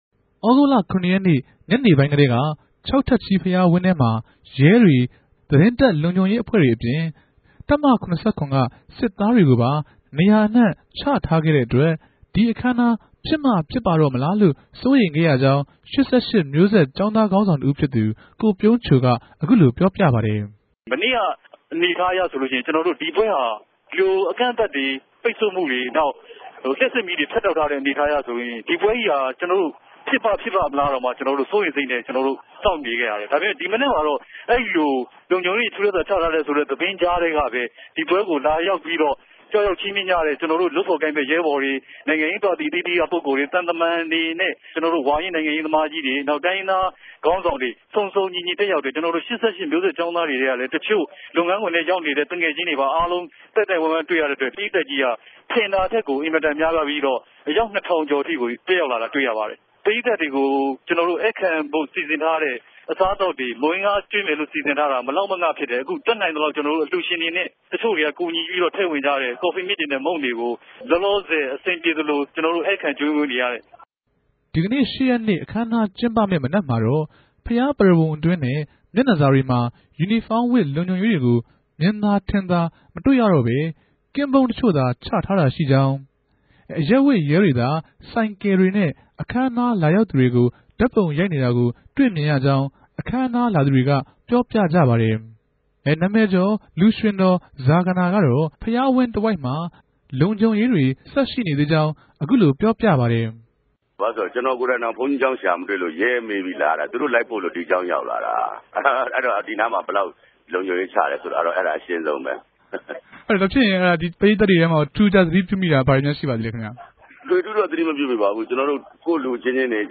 ရန်ကုန်က ရြစ်လေးလုံး ၁၈ ိံြစ်ူပည်ႛ ဝၝဆိုသကဿန်းကပ်လြပြြဲနဲႛ ပတ်သက်္ဘပီး ၈၈ မဵြိးဆက် ကေဵာင်းသားခေၝင်းဆောင်တြေ၊ ဧည်ႛပရိသတ်တေကြို ဆက်သြယ်မေးူမန်း္ဘပီး စုစည်းတင်ဆက်ခဲ့တာ ူဖစ်ပၝတယ်။